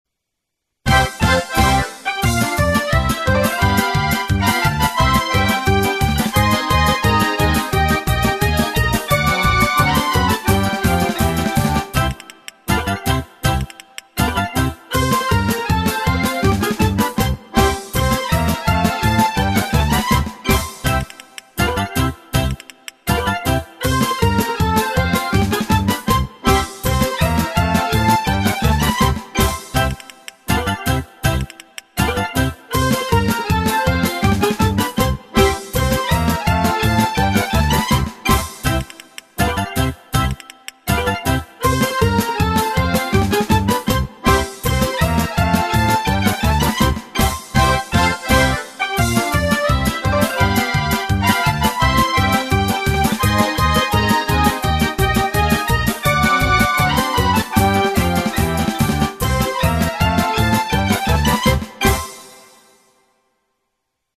Прослушать минусовку